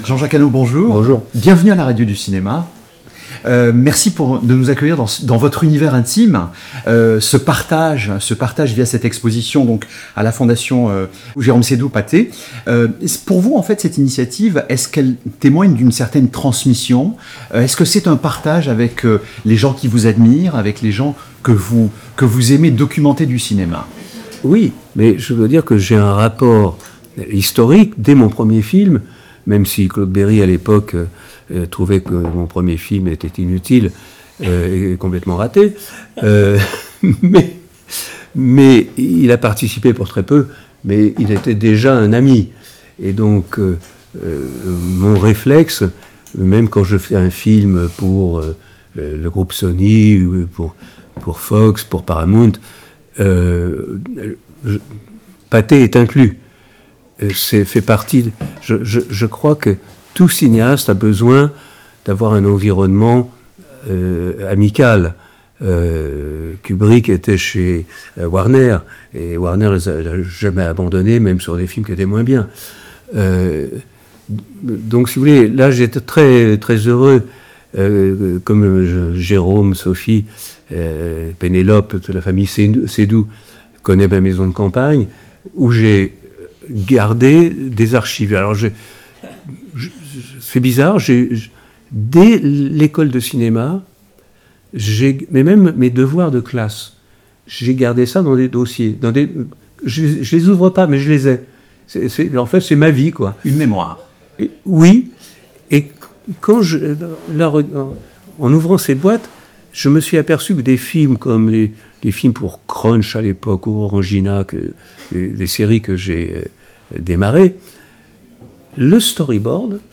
Une conversation de « faiseur » au sens noble: un cinéaste qui revendique le travail, la préparation, la troupe.